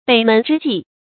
北门之寄 běi mén zhī jì
北门之寄发音
成语注音 ㄅㄟˇ ㄇㄣˊ ㄓㄧ ㄐㄧˋ